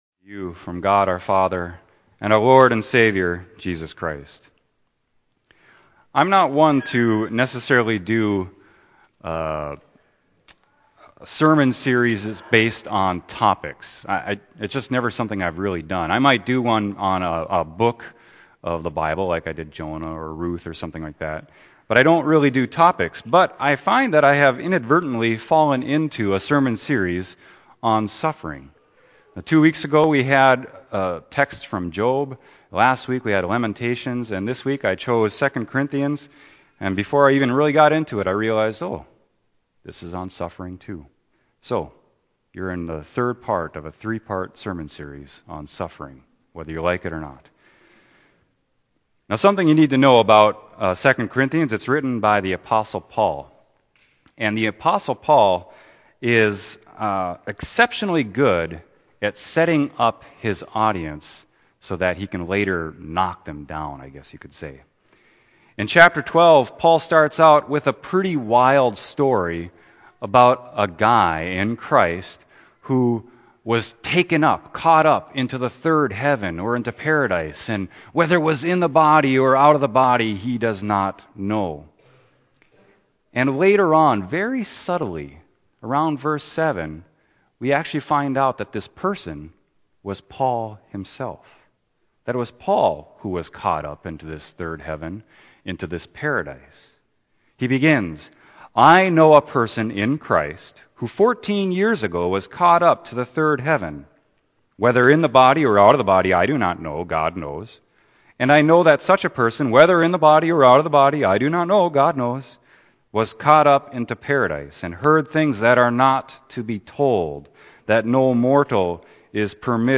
Sermon: 2 Corinthians 12.2-10